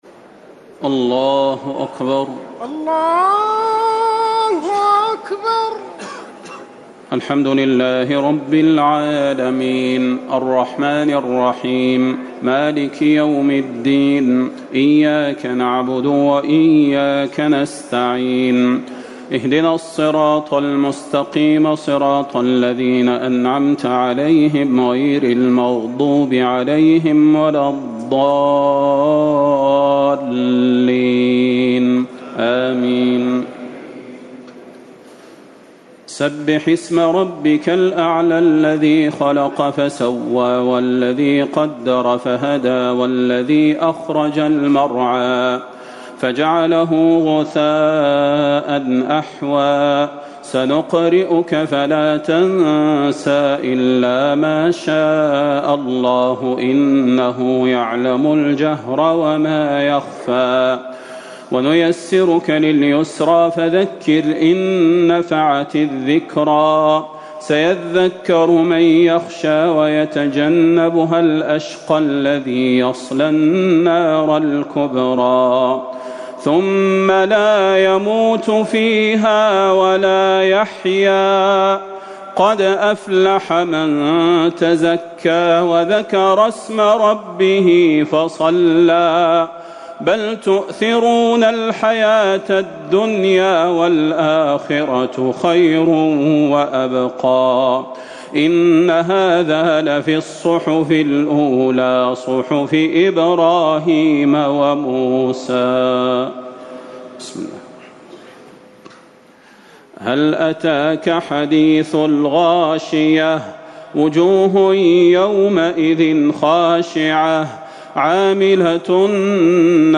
تراويح ٢٩ رمضان ١٤٤٠ من سورة الأعلى - الناس > تراويح الحرم النبوي عام 1440 🕌 > التراويح - تلاوات الحرمين